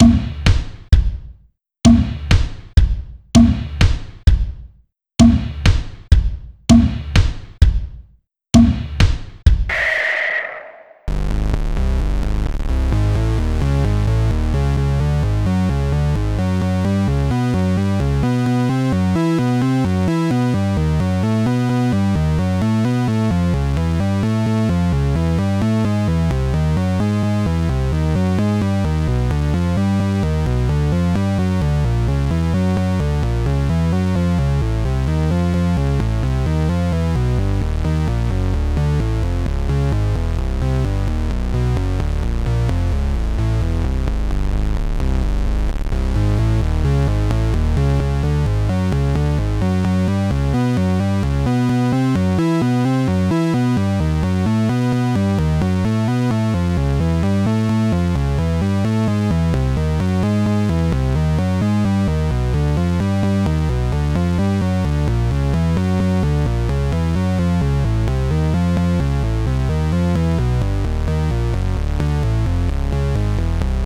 vgm,